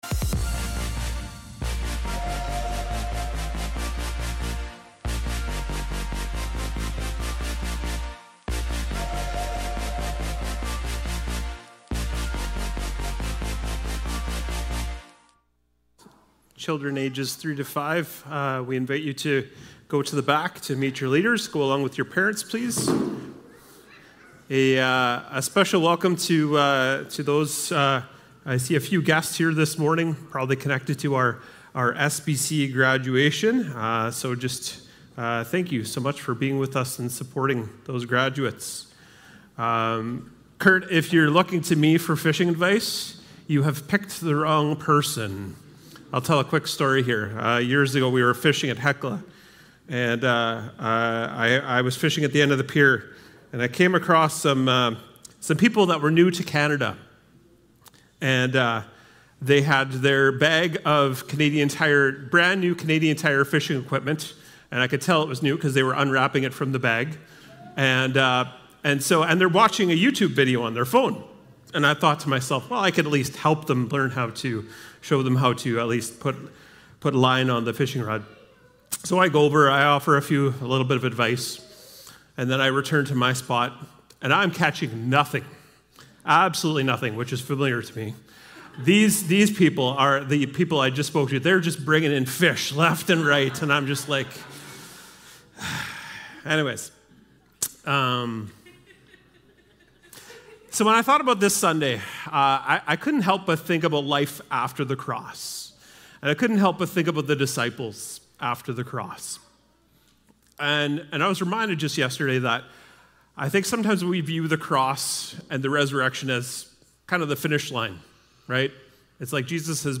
April-27-Service.mp3